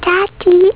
LA VOCE DI MAGGIE ^_^
maggie_prima_parola.au